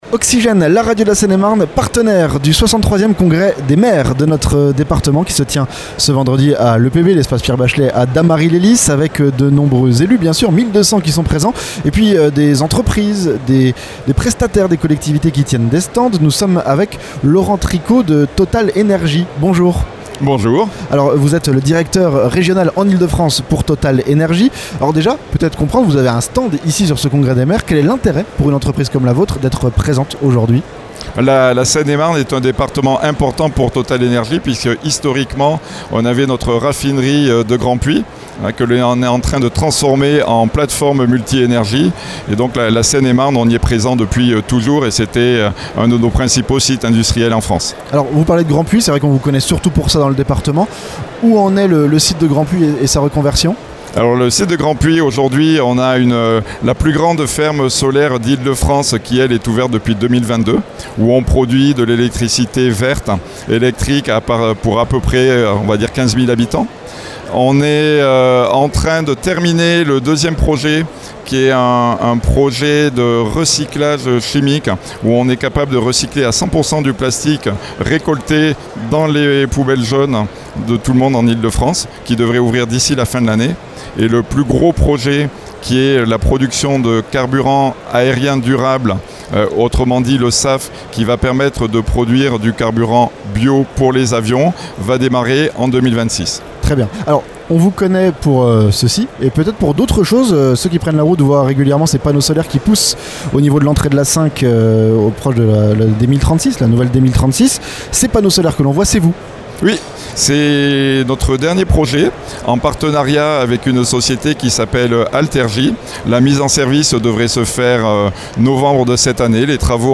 CONGRES DES MAIRES - Entretien